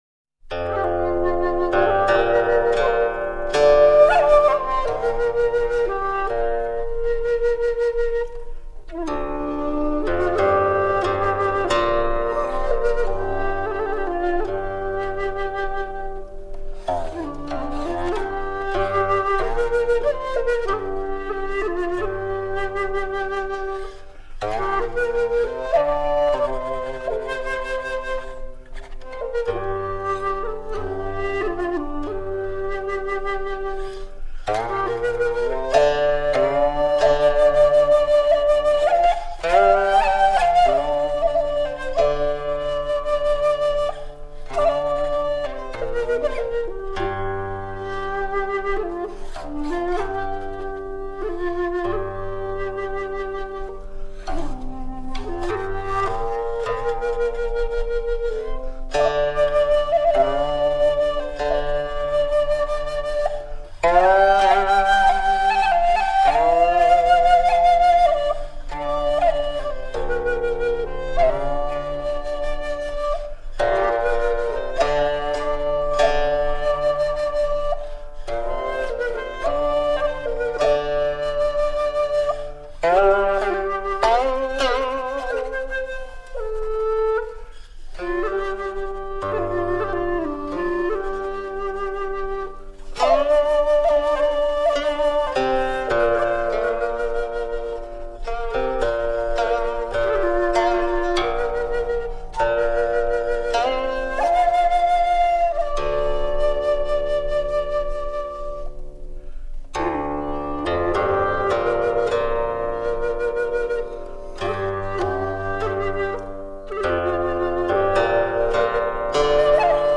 古琴音乐
古琴
是一张琴箫合奏的音乐唱片